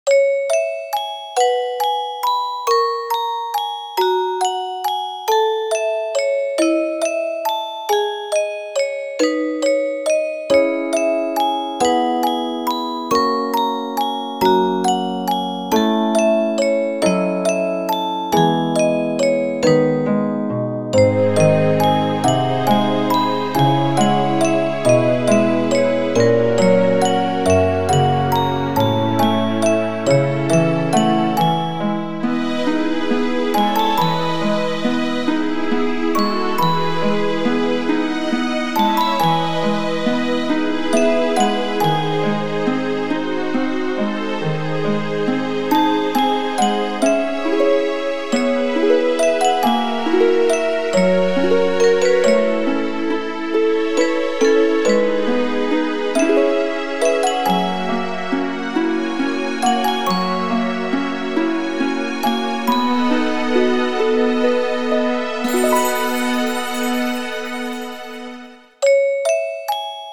ogg(L) 悲しみ オルゴール 希望